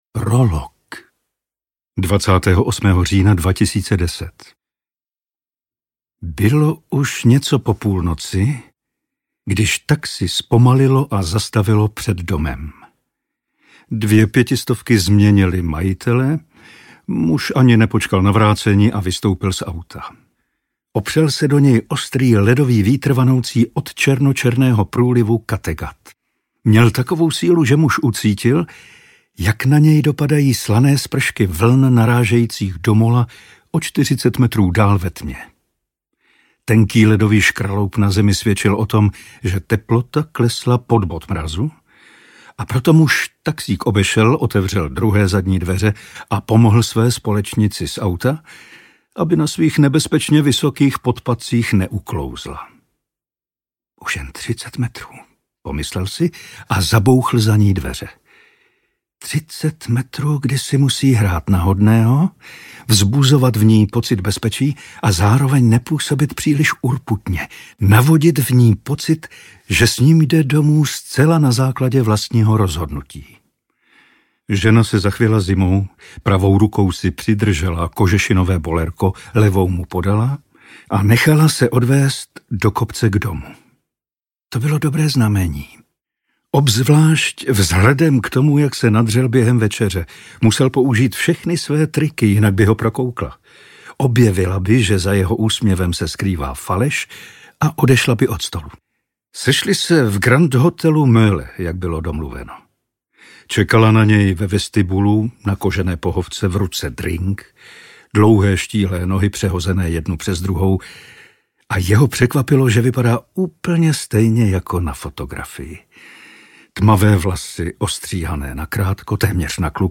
Osmnáct pod nulou - 2. vydání audiokniha
Ukázka z knihy